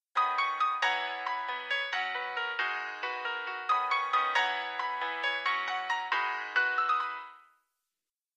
逆にHPFを通すと
あ、なんか今度は逆にきんきんした音になったよっ。